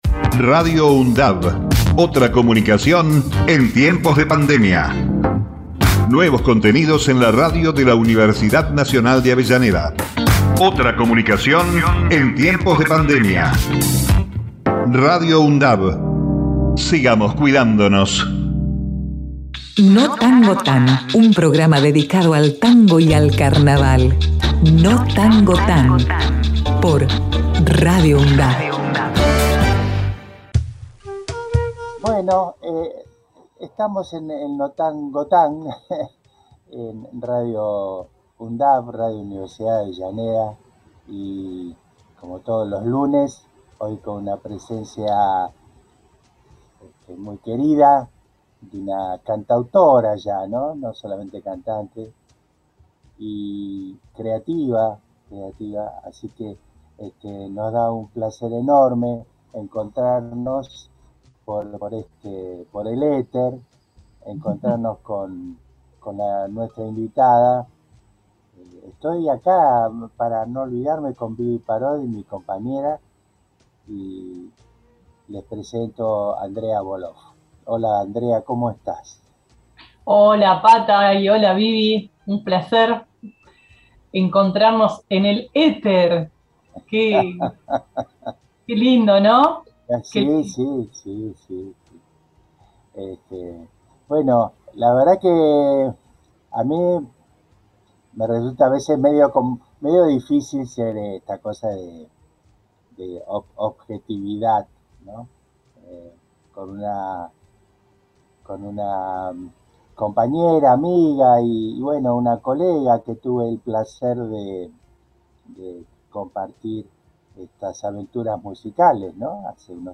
cantante y letrista de tango en sus formaciones recientes